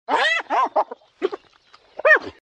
На этой странице собраны разнообразные звуки гиены: от характерного смеха до агрессивного рычания.
Короткий хохот гиены